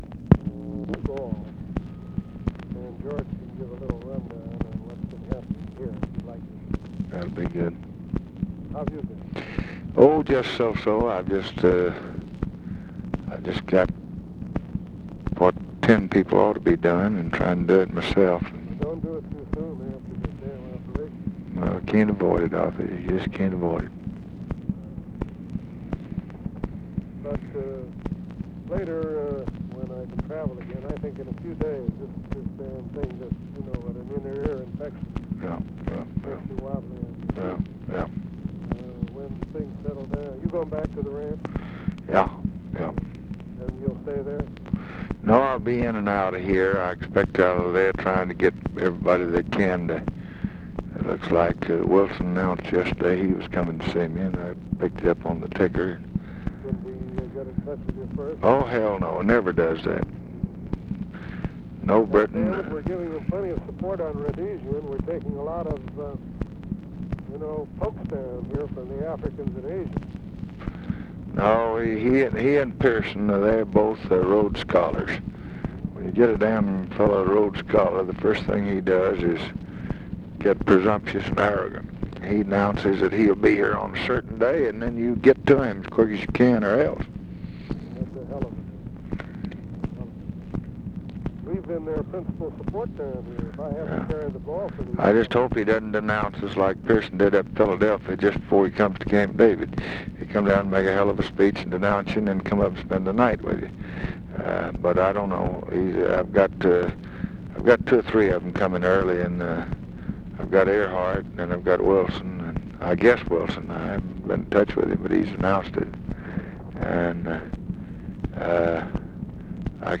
Conversation with ARTHUR GOLDBERG, November 18, 1965
Secret White House Tapes